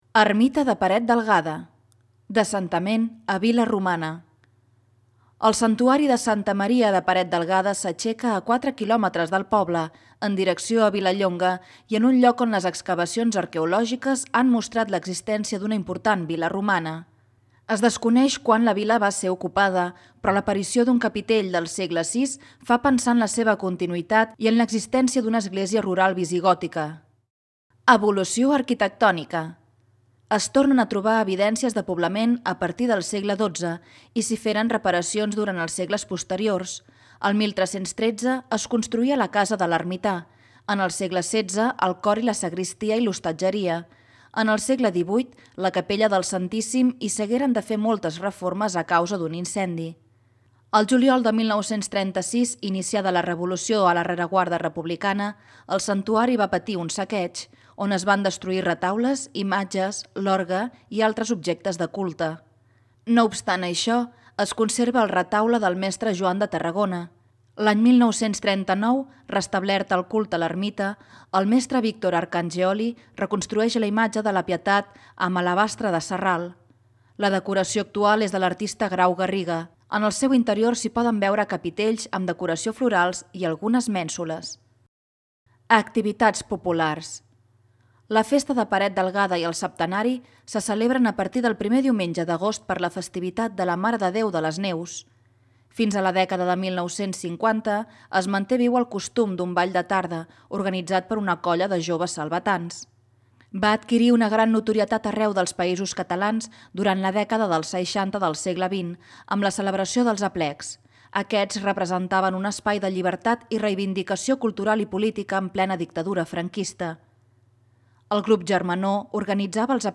Audio guia